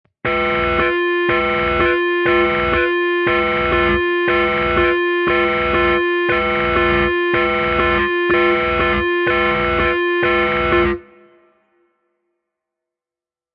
Download Emergency sound effect for free.